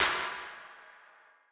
CLAP - DROWNER.wav